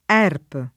vai all'elenco alfabetico delle voci ingrandisci il carattere 100% rimpicciolisci il carattere stampa invia tramite posta elettronica codividi su Facebook ERP [ $ rp ] n. pr. m. — sigla di European Recovery Program (ingl.)